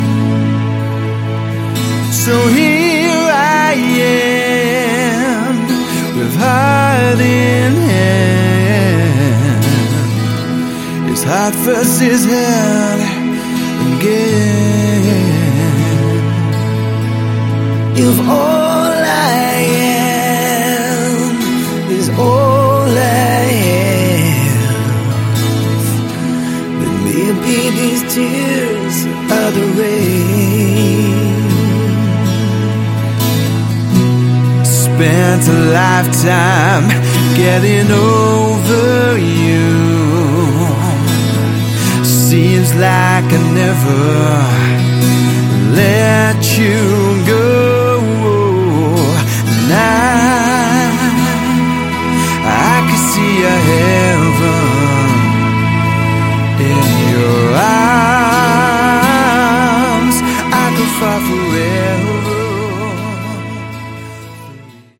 Category: AOR
lead and backing vocals, guitar, bass, drums